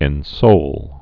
(ĕn-sōl)